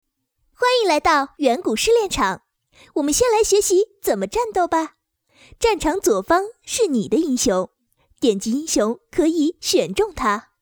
国语少年素人 、女动漫动画游戏影视 、看稿报价女游11 国语 女声 游戏 英雄联盟角色模仿-42vn 素人